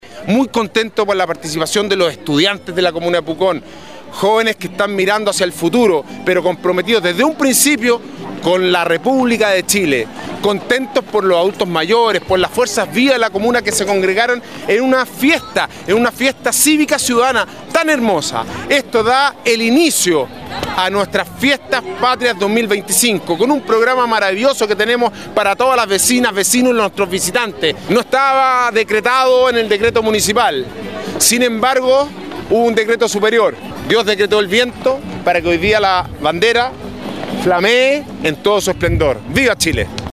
Alcalde-Sebastian-Alvarez-destaca-el-evento-y-la-presencia-del-Puelche-.mp3